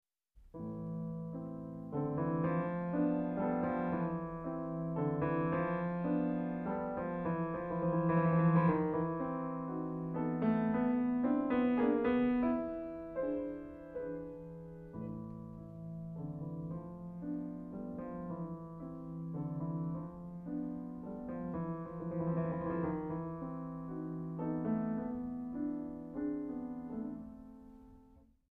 Pianist
refined, patrician touch
A minor